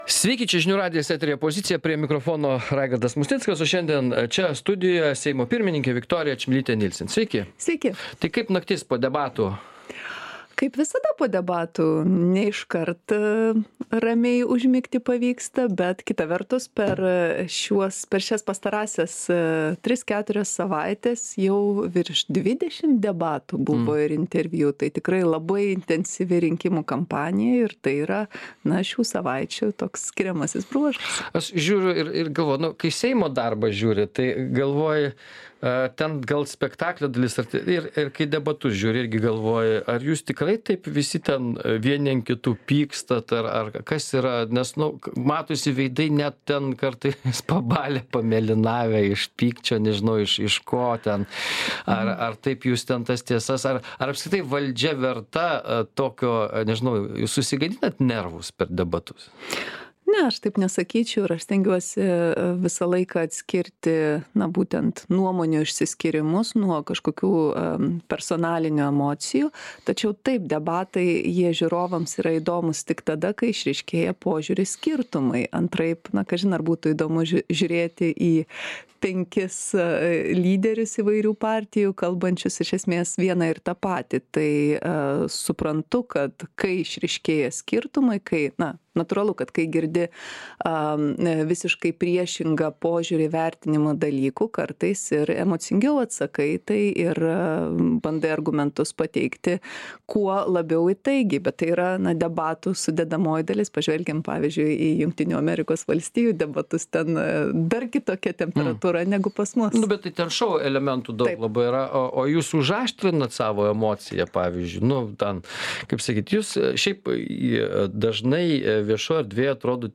Laidoje dalyvauja Seimo pirmininkė Viktorija Čmilytė-Nielsen.